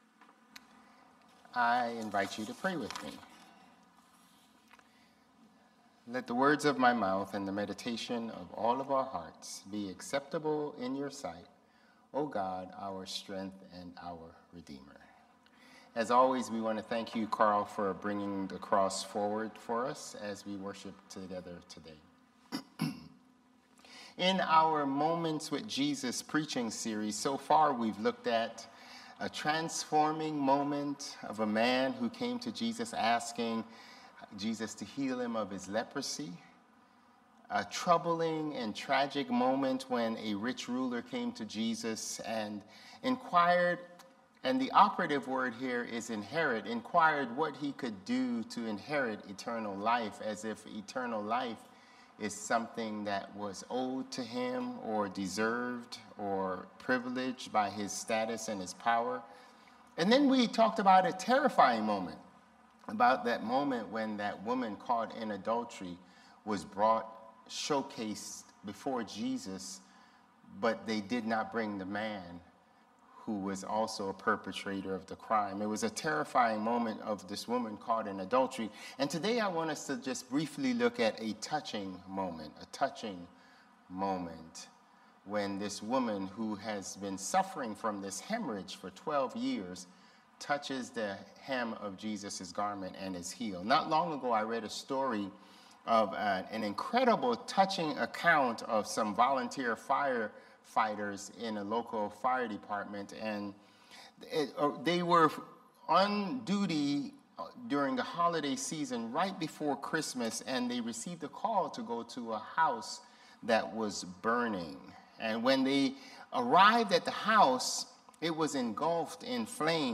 Sermons | Bethel Lutheran Church